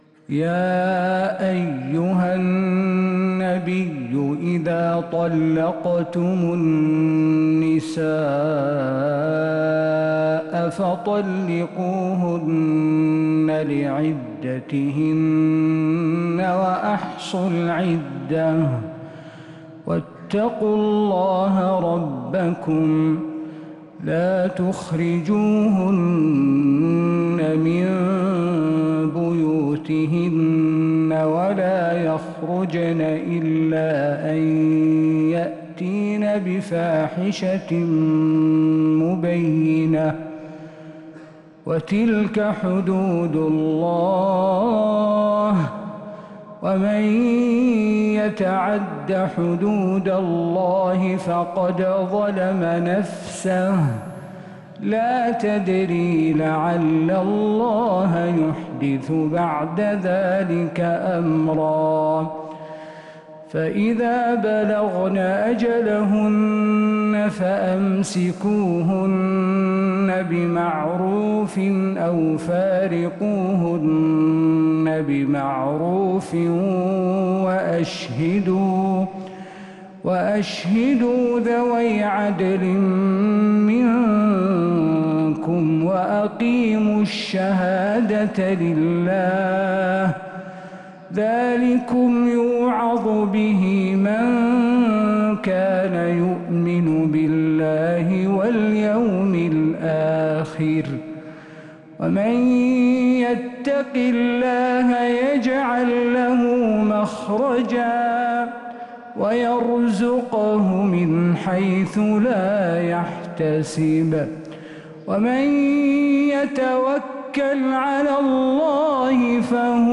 من الحرم النبوي